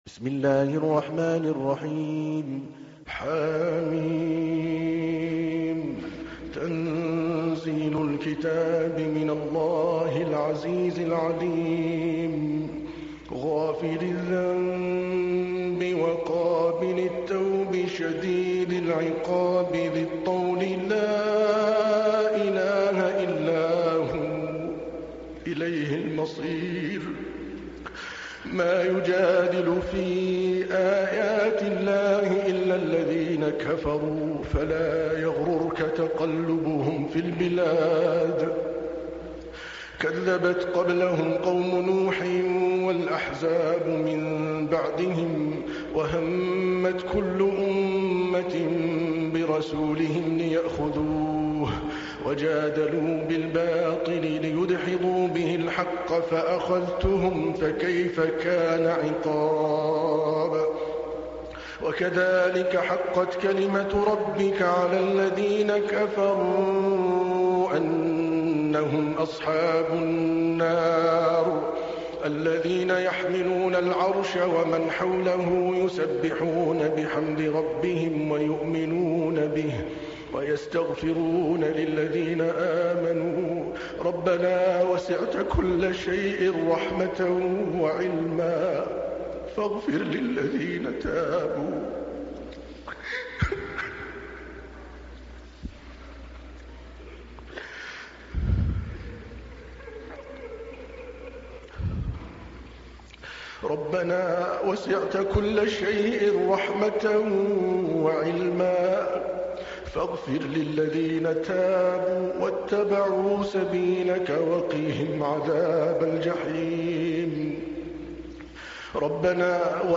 تحميل : 40. سورة غافر / القارئ عادل الكلباني / القرآن الكريم / موقع يا حسين